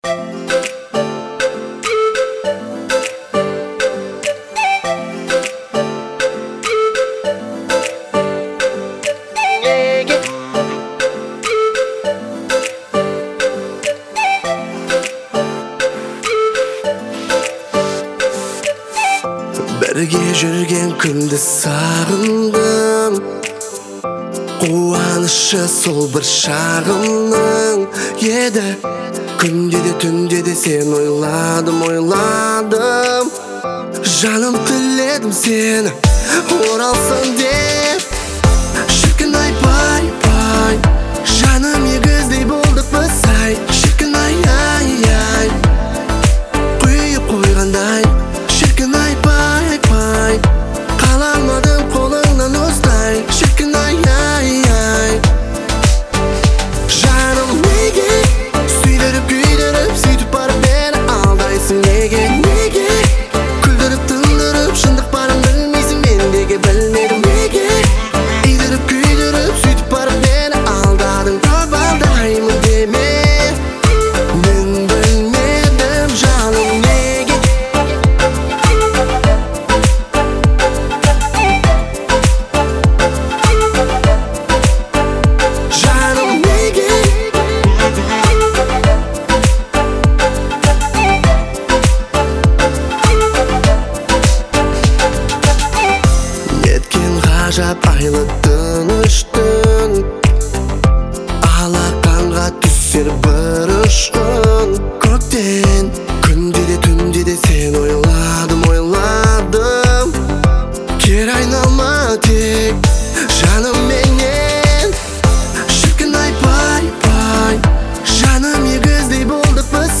насыщенными мелодиями и эмоциональным вокалом